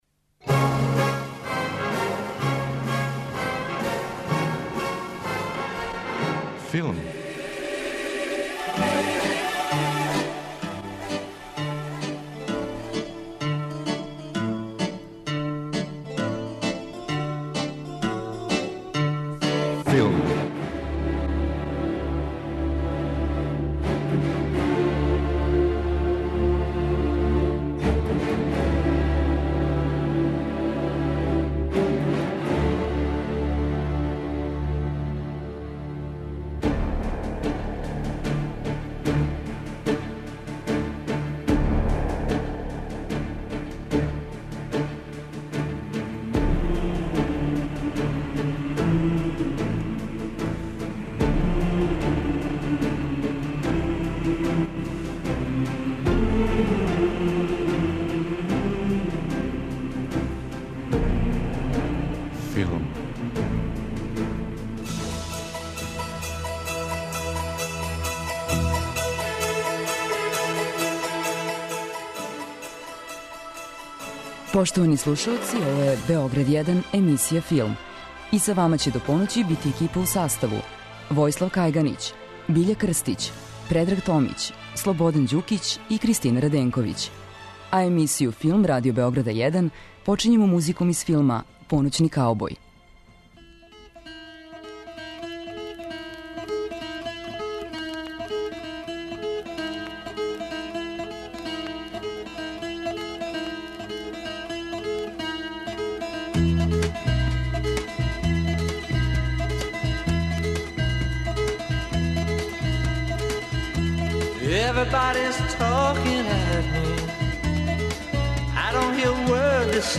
Два наслова наших репортажа: офталмолози са Каленић пијаце и медари из Лесковца.